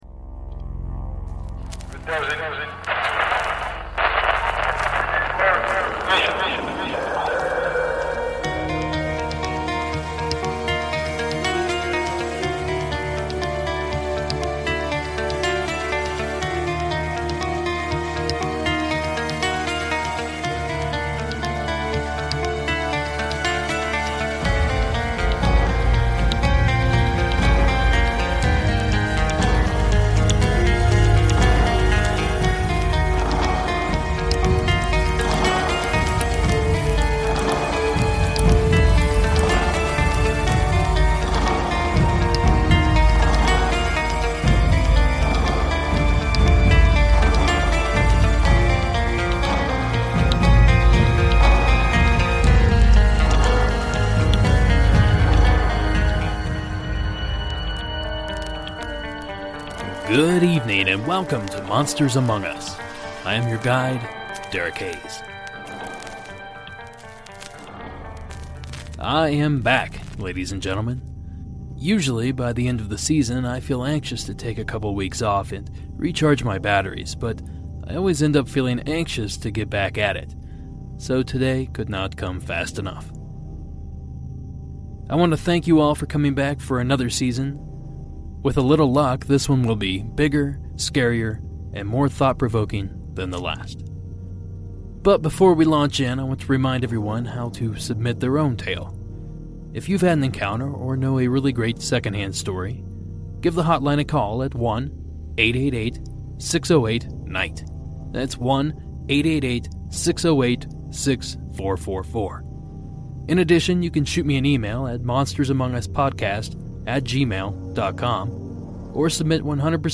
Witnesses of bigfoot, sasquatch, ufo's, aliens, ghosts and an array of other creatures from the paranormal and cryptozoology realm detail their encounters.